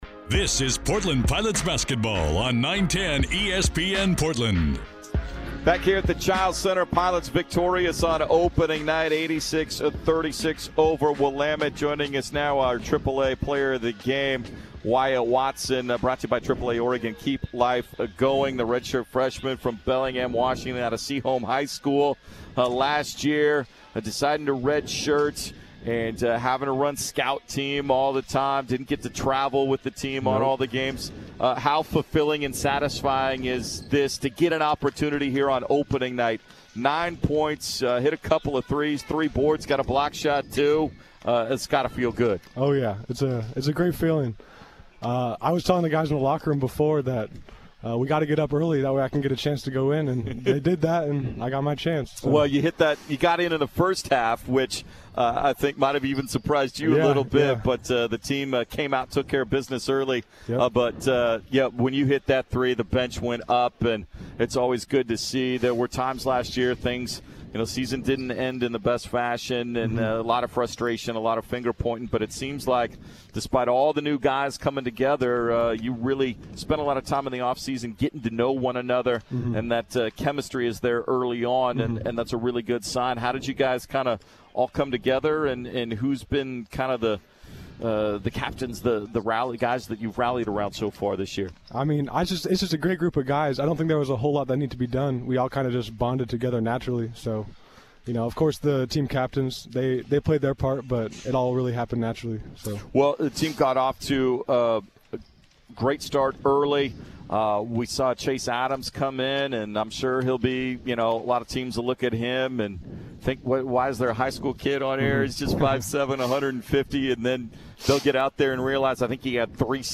Men's Hoops Post-Game Interviews vs. Willamette